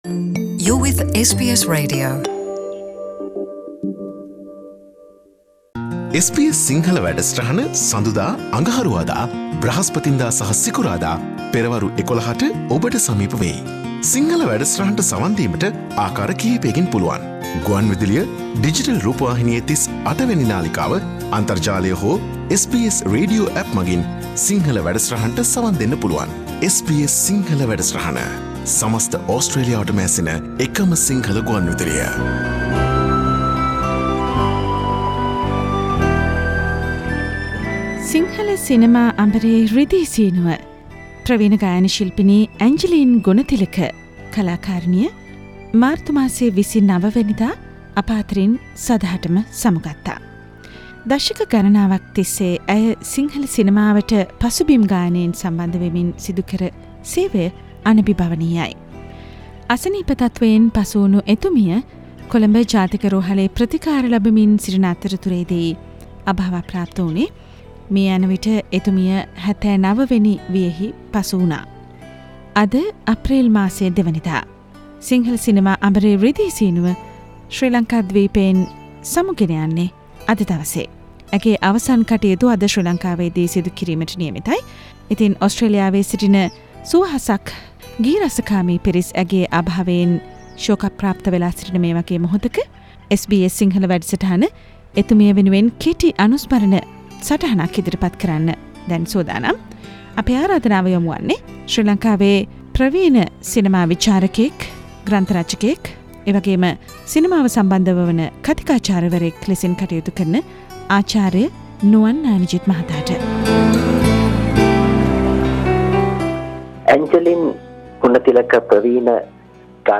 SBS Sinhala radio program for late Sri Lankan renowned screen vocalist Angeline Gunathilake.